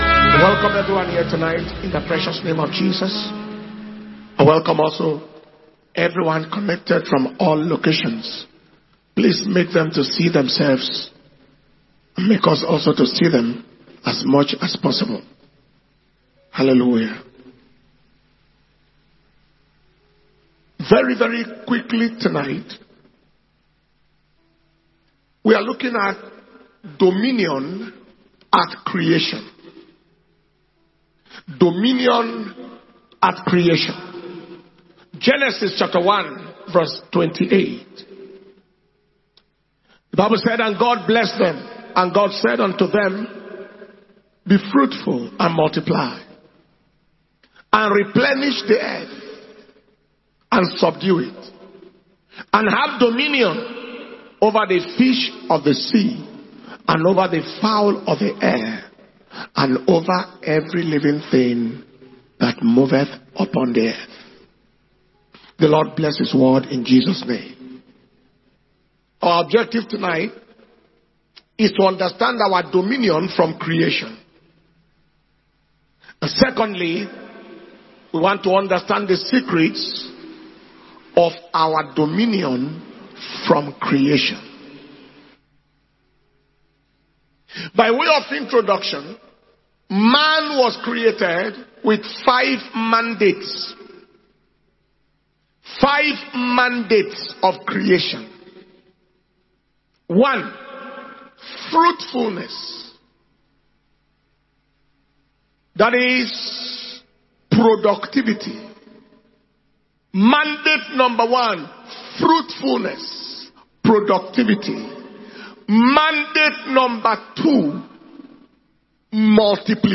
Kingdom Power and Glory World Conference 2024 day 1 Evening session